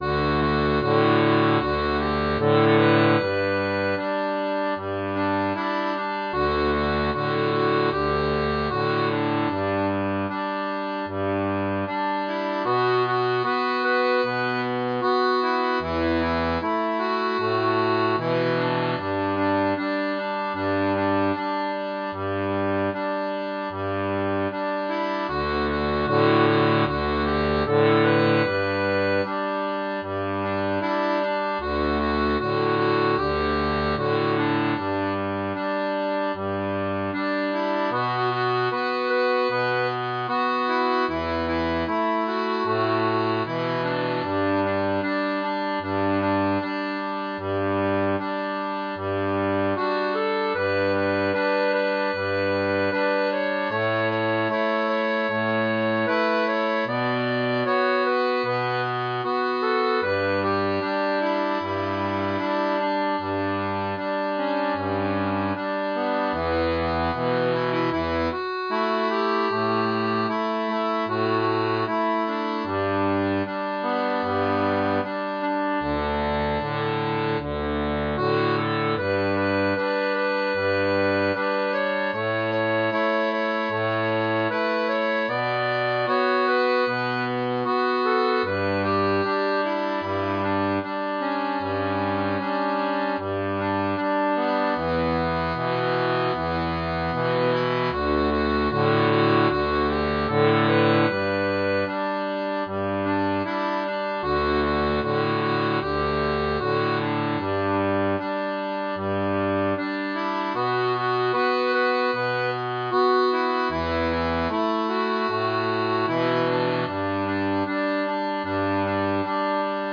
• Une tablature transposée en D pour diato à 3 rangs
Chanson française